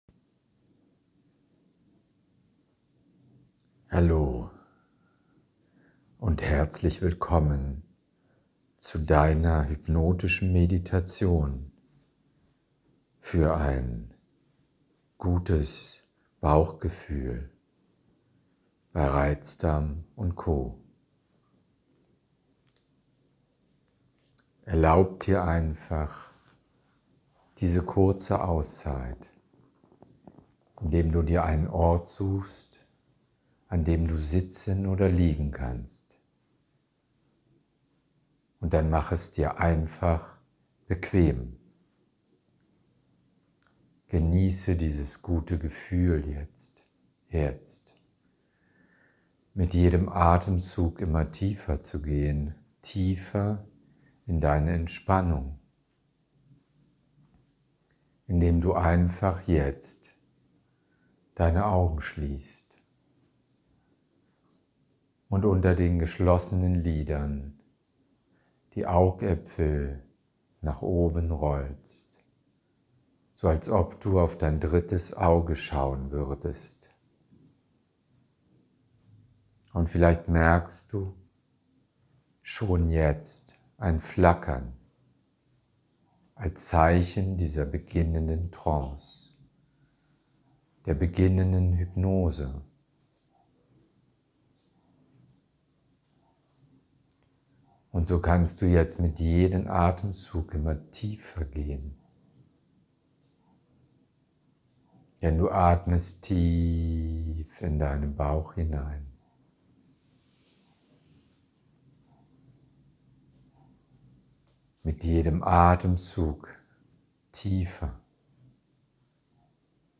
Brauchwohl-hypnotische-Meditation